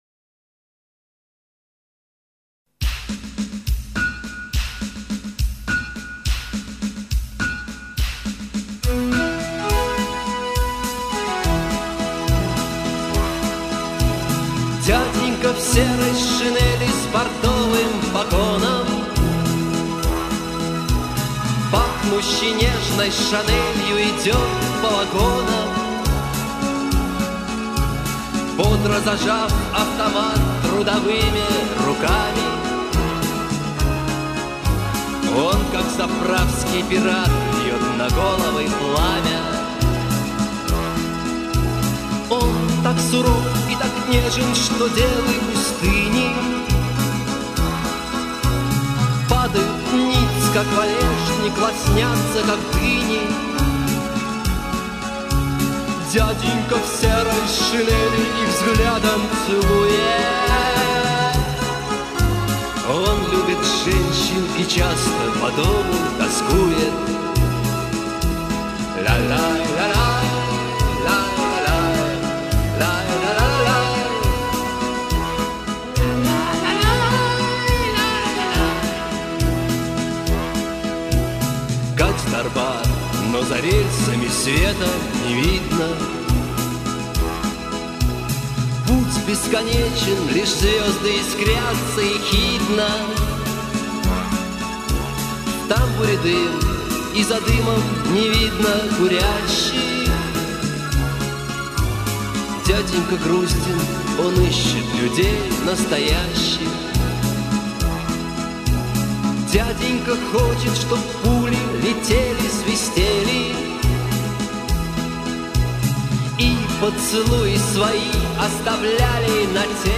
Made in Monday-City (Dushanbe).